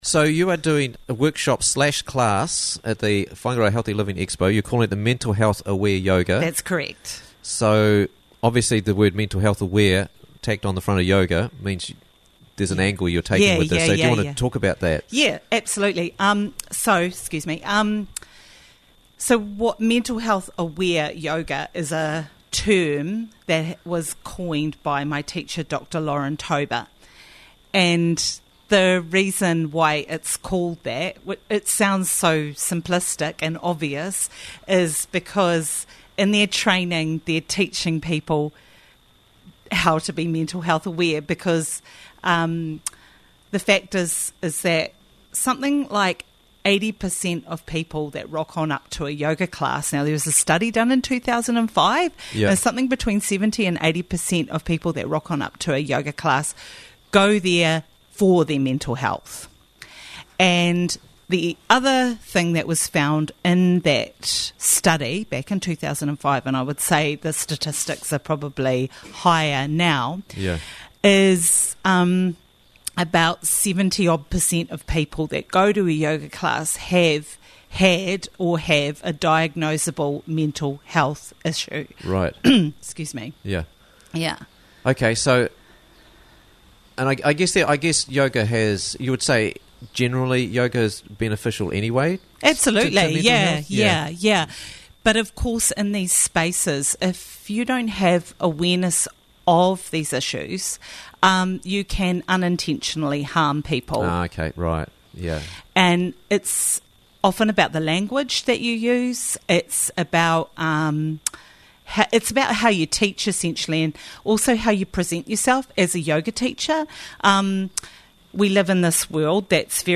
Try Mental Health Aware Yoga - Interviews from the Raglan Morning Show